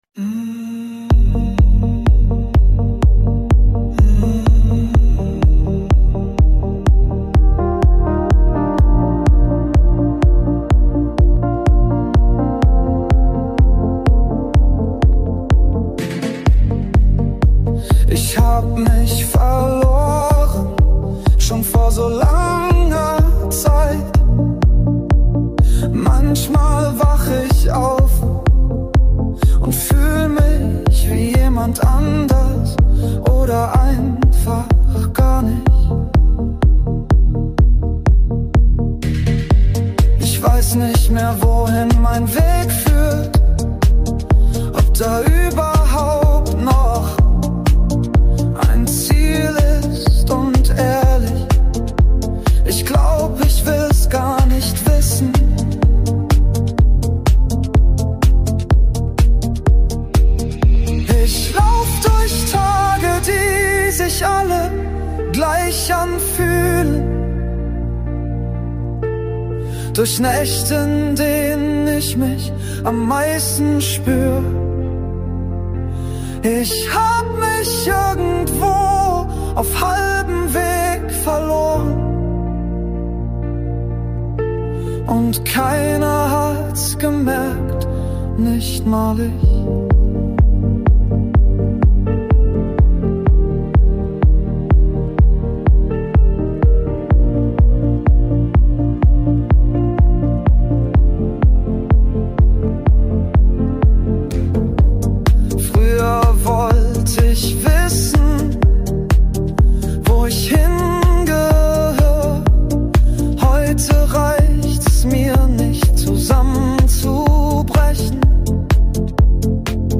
recht poppig